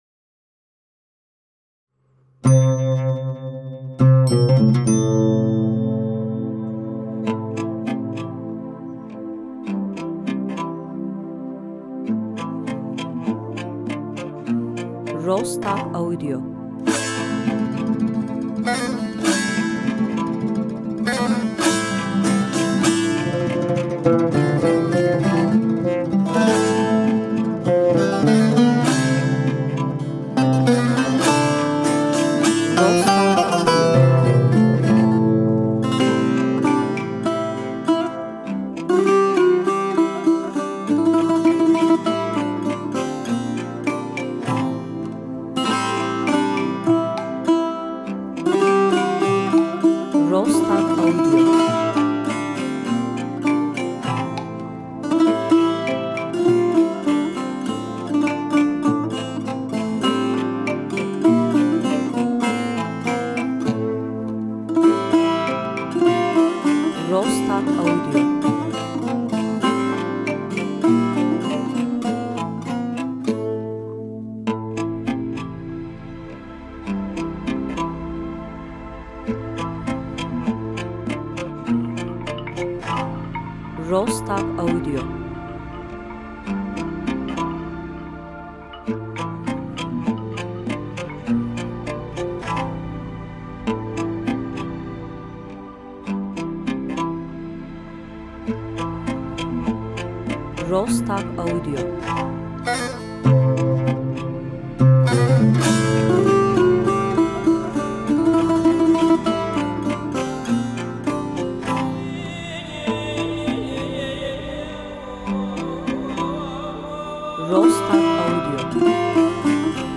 enstrümantal duygusal epik